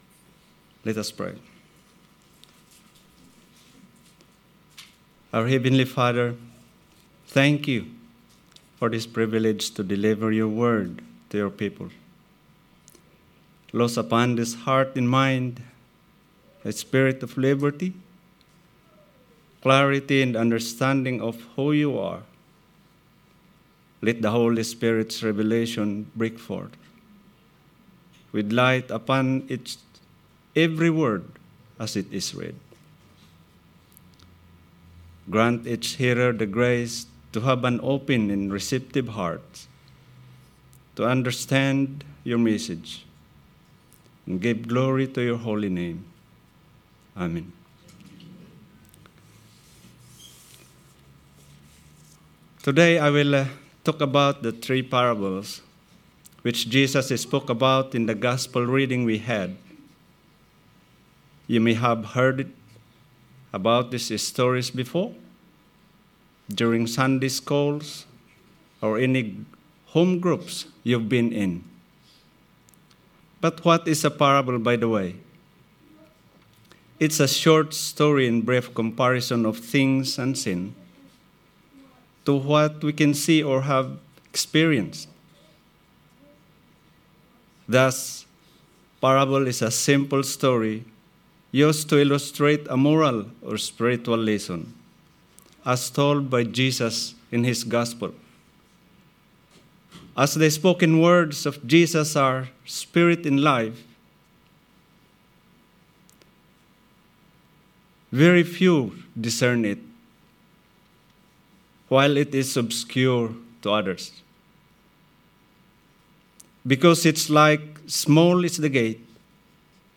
Sermon 30th July – A Lighthouse to the community